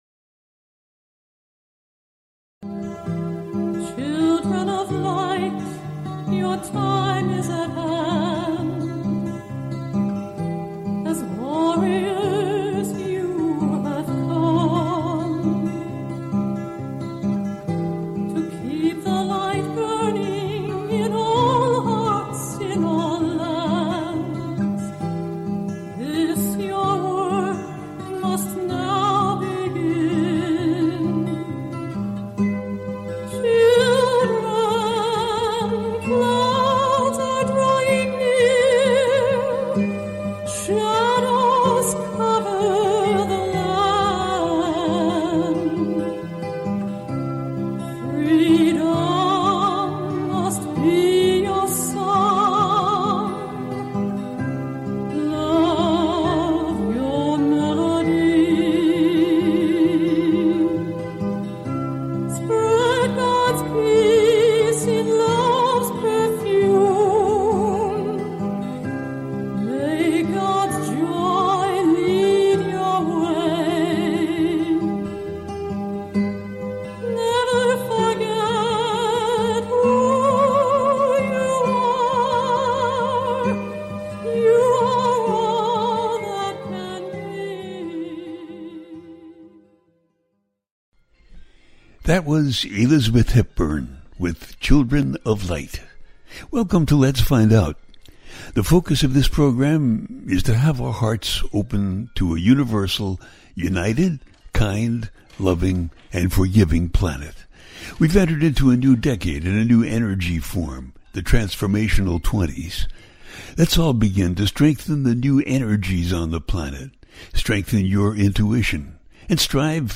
The listener can call in to ask a question on the air.
Each show ends with a guided meditation.